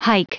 Prononciation du mot hike en anglais (fichier audio)
Prononciation du mot : hike